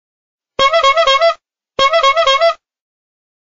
*HOOOOOOOOOOOOOOOOOOOOOOOONK*